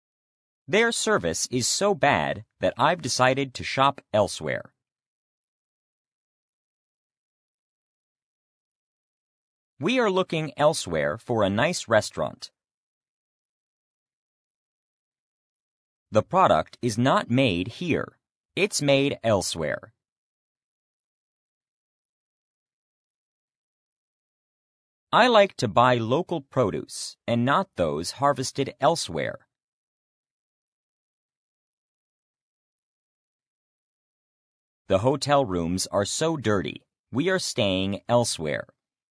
elsewhere-pause.mp3